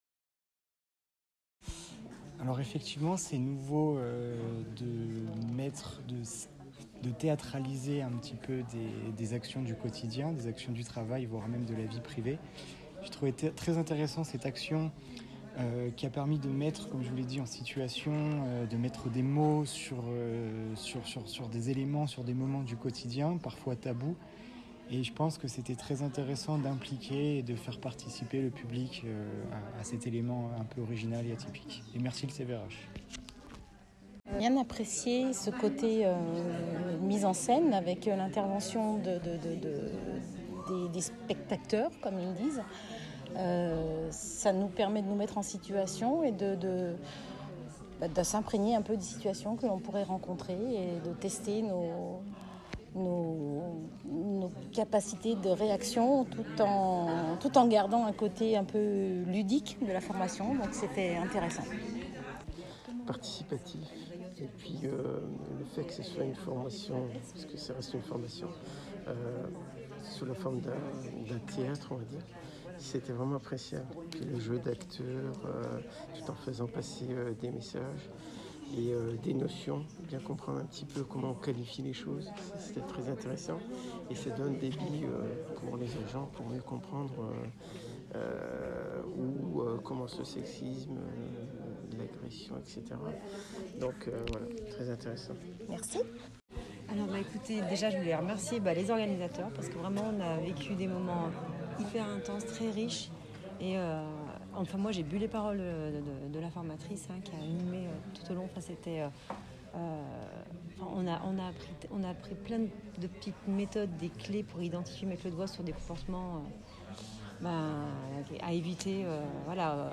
Réactions à la sortie de la formation !